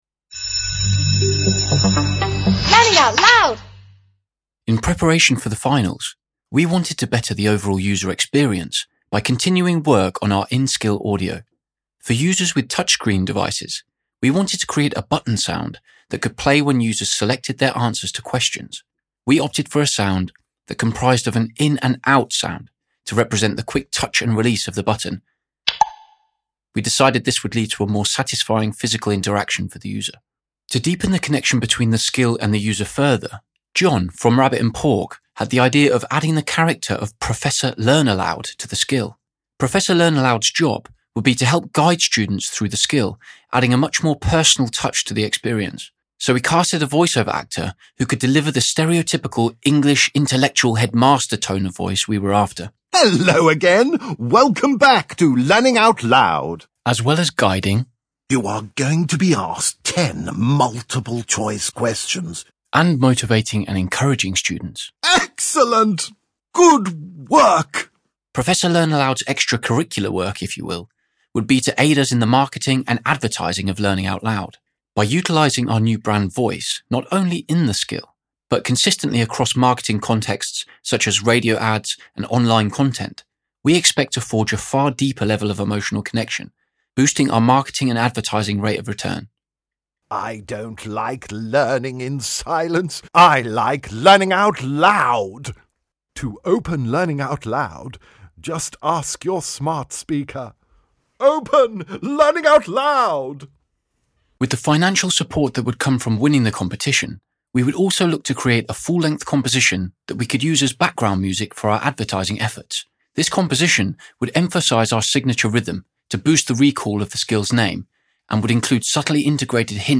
Implemented APL for Audio to provide a richer sound experience with new sound effects and voices.